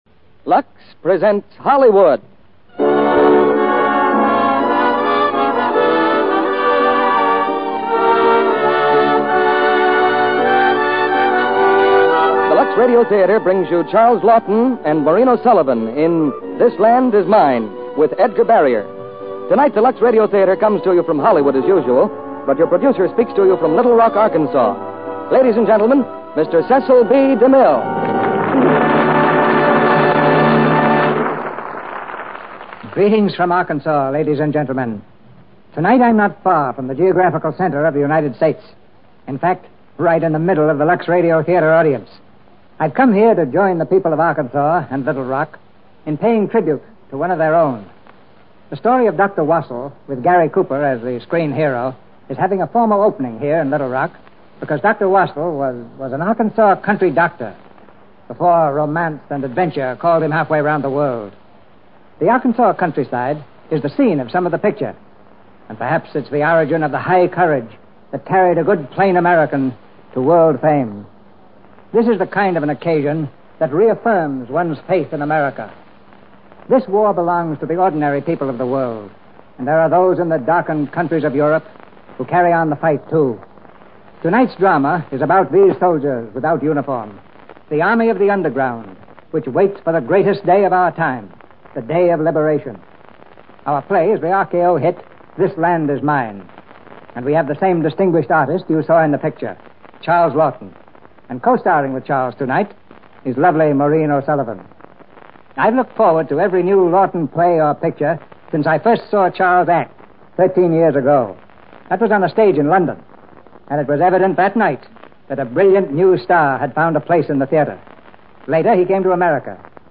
starring Charles Laughton, Maureen O'Sullivan, Edgar Barrier